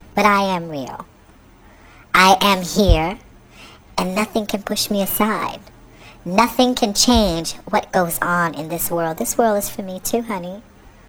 The changing of pitch of this recording, starting with the same pattern of distortion, relates to the changes of voice that occur during the process of transition, as well as serves as a recognition of trans musical artists who edit their voice’s pitch, today mainly seen in the hyperpop genre.
Pitched_Up_1.wav